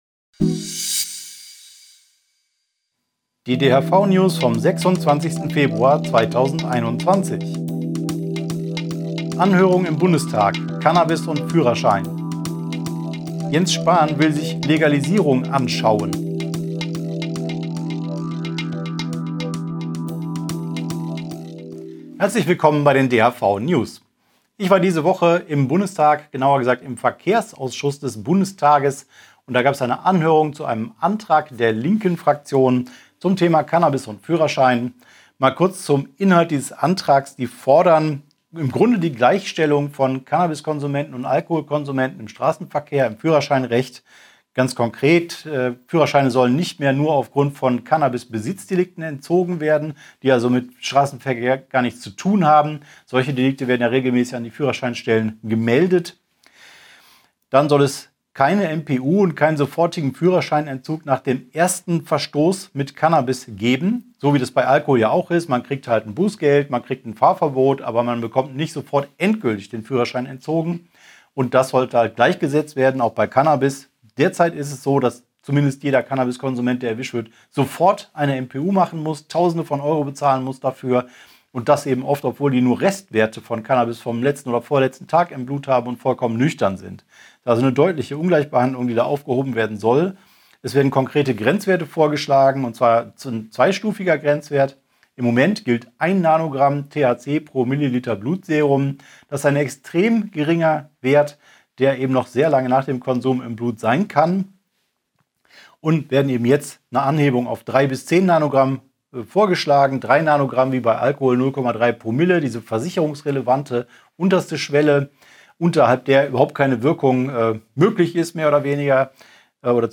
DHV-Video-News #283 Die Hanfverband-Videonews vom 26.02.2021 Die Tonspur der Sendung steht als Audio-Podcast am Ende dieser Nachricht zum downloaden oder direkt hören zur Verfügung.